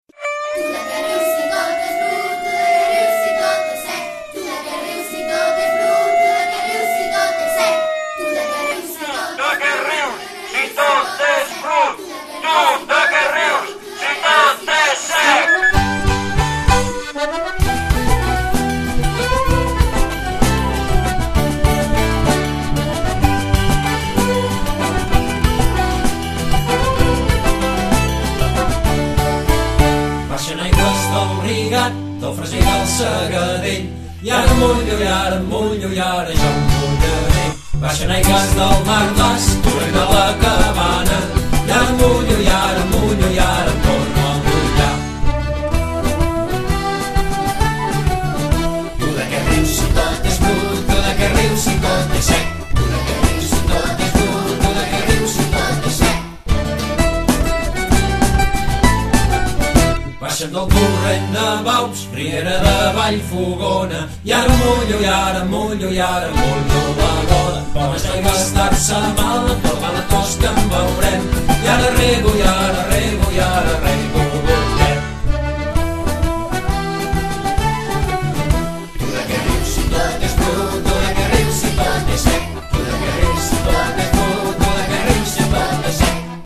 Els rius i rieres fan goig de veure… torna a córrer l’aigua per tot arreu i els pantans ja s’estan omplint. Contents d’aquesta notícia, els Randellaires de Ripoll, acompanyats dels nens i nenes de les escoles del Ripollès, canten aquesta bonica cançó tot celebrant que l’aigua torna a omplir els nostres rius.